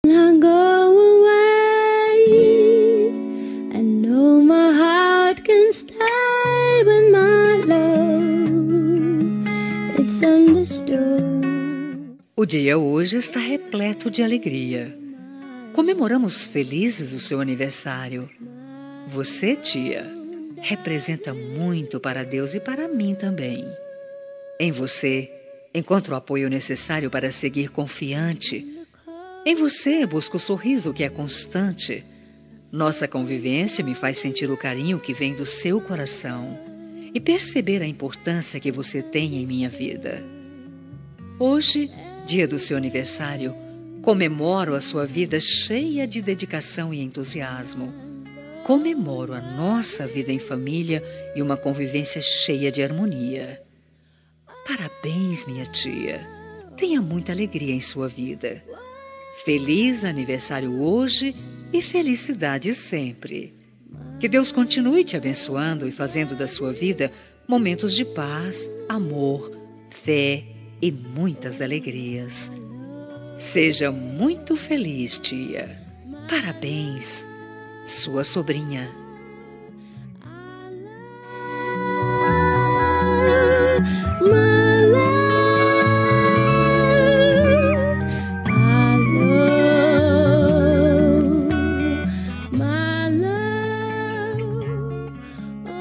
Telemensagem Aniversário de Tia – Voz Feminina – Cód: 4262